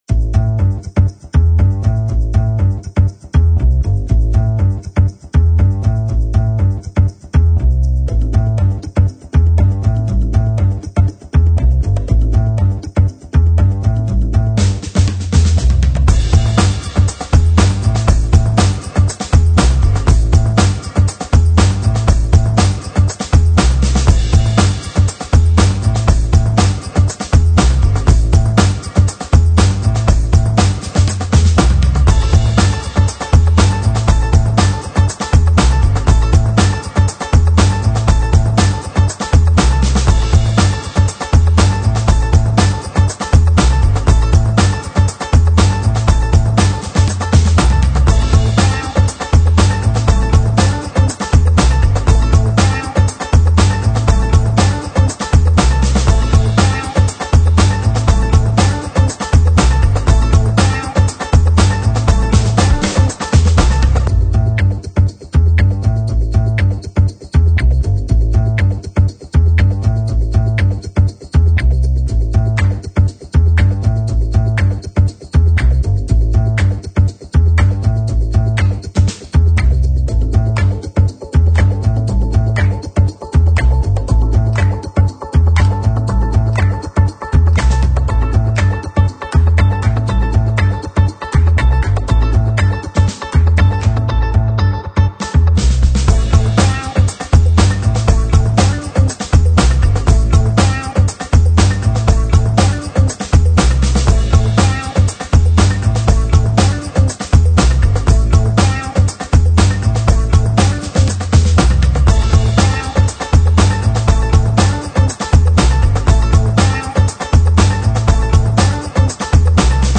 描述：正面时髦的背景音乐与朗朗上口的低音槽和打破鼓。